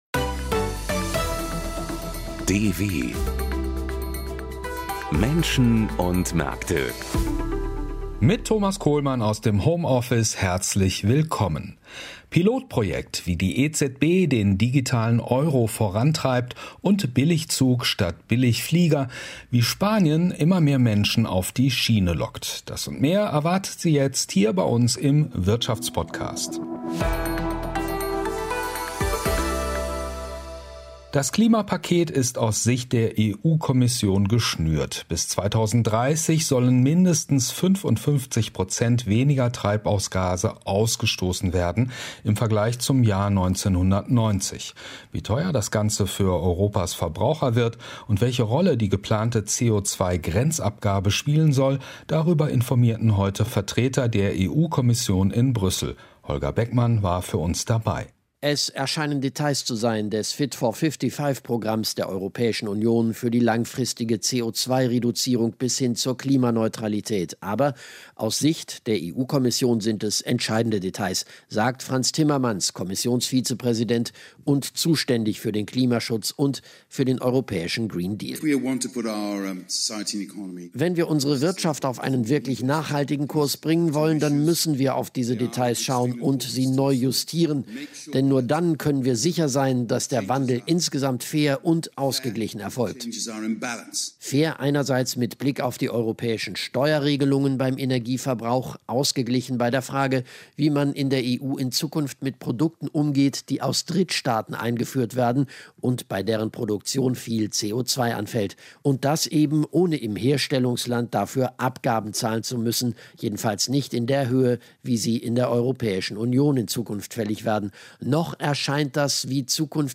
Redakteur im Studio